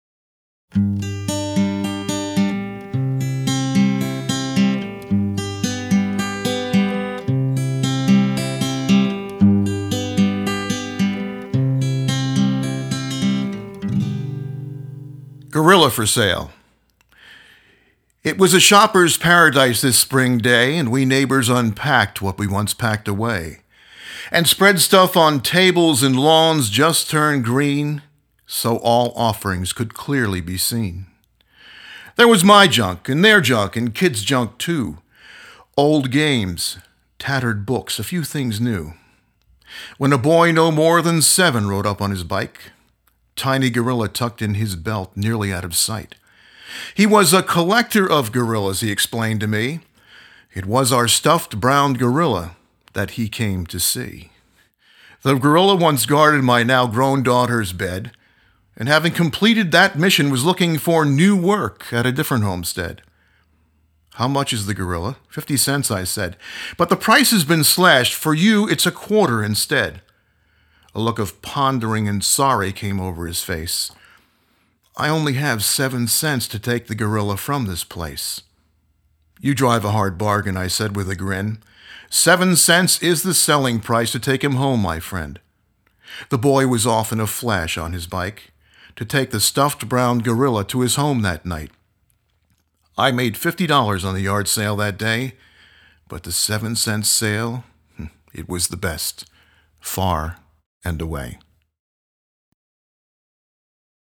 Click here for a reading by the poet